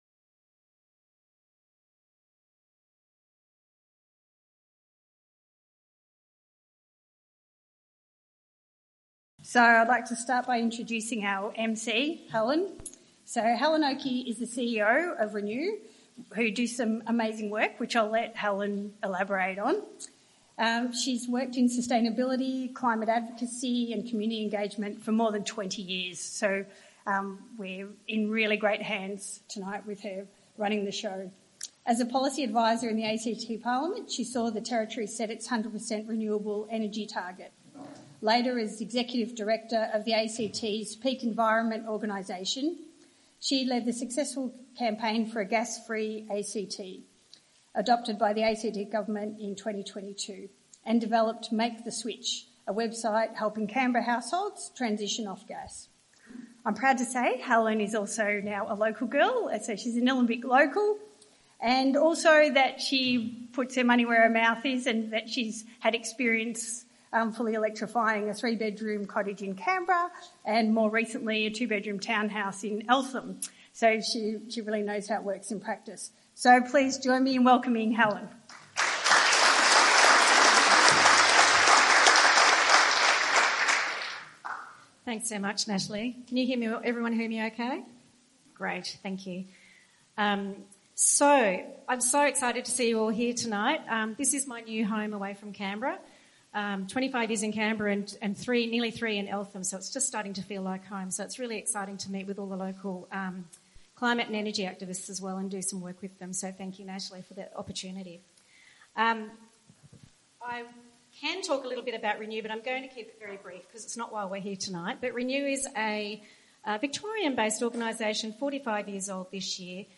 Thanks to the amazing efforts of our volunteers, we have audio and video recordings of the event to share. Find out how and why we need to electrify everything!